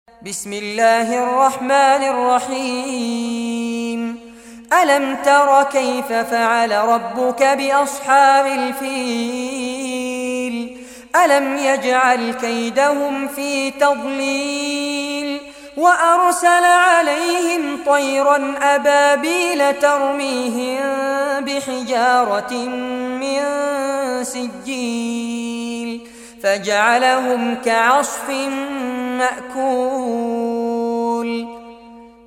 Surah Al-Fil Recitation by Sheikh Fares Abbad
Surah Al-Fil, listen or play online mp3 tilawat / recitation in Arabic in the beautiful voice of Sheikh Fares Abbad.